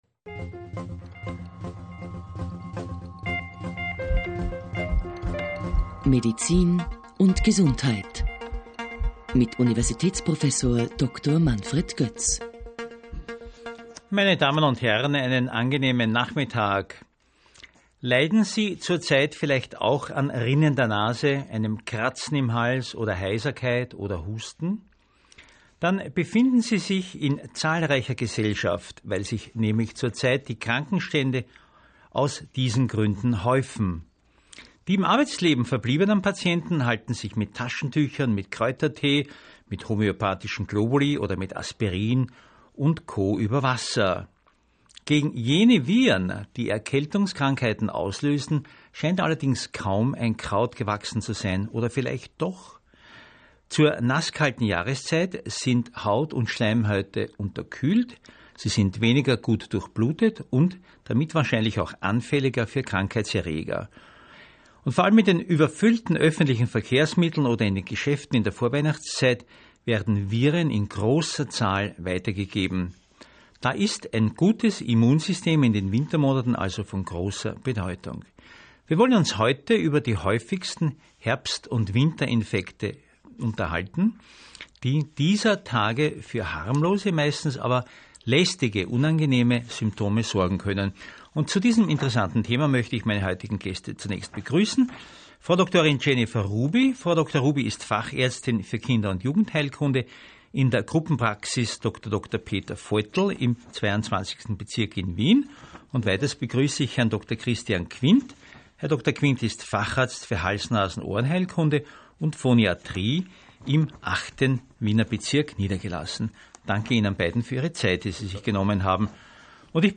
Radiobeitrag Ö1 - Erkältungen (Ö1, Oktober 2016)